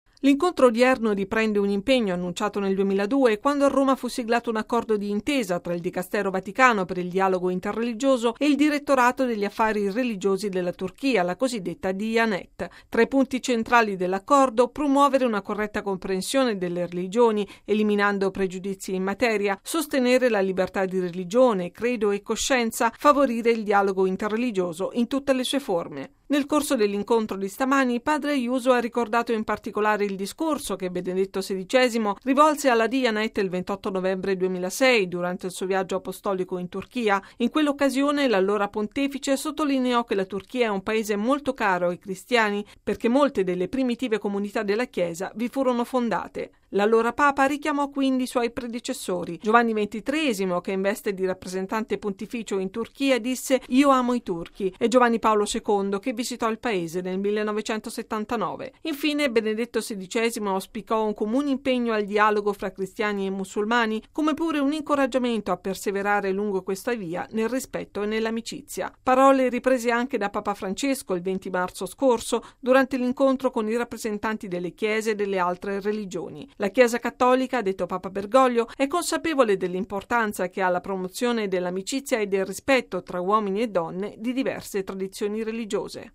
◊   Rilanciare un dialogo interreligioso di amicizia e di rispetto: è stato questo l’intento dell’incontro ufficiale tenutosi stamani ad Ankara, in Turchia, tra padre Miguel Ángel Ayuso Guixot, segretario del Pontificio Consiglio per il Dialogo Interreligioso, e il presidente del Direttorato degli Affari religiosi della Turchia (Diyanet), Mehmet Görmez. Il servizio